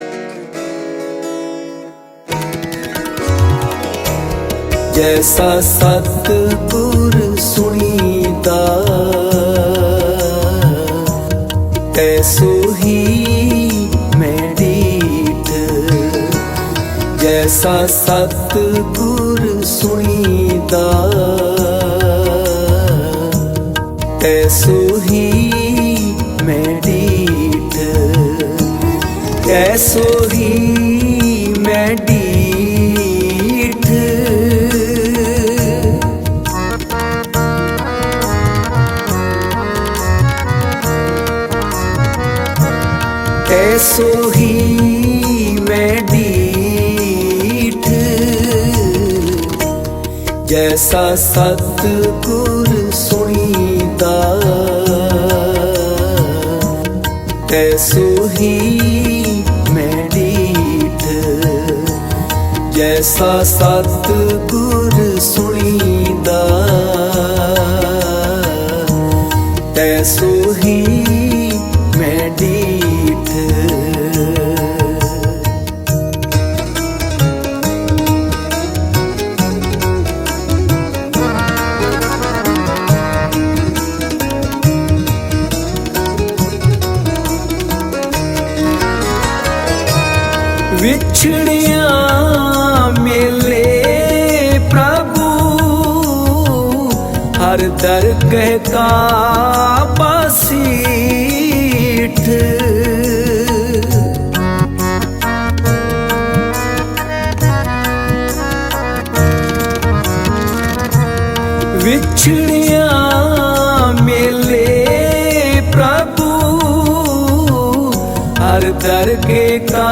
Gurbani Kirtan